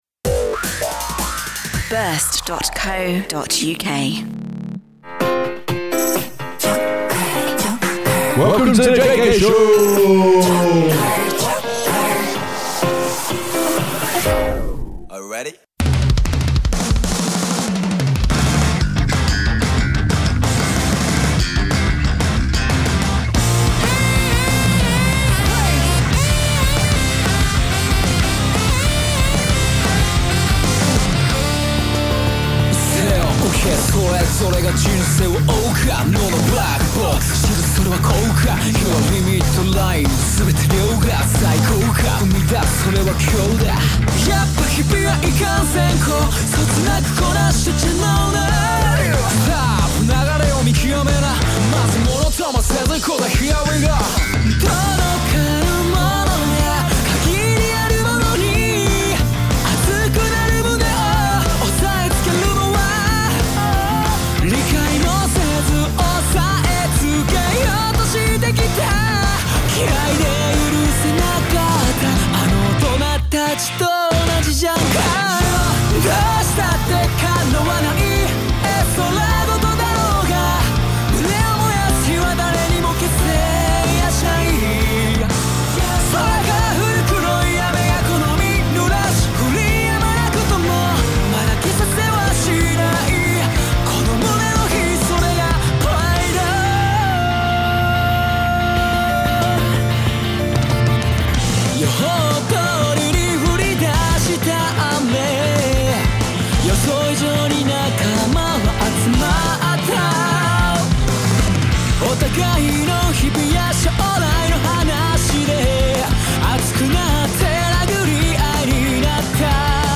Jpop, J-Metal, Kpop, K-Rock and even K-Hip Hop.
Song of the Show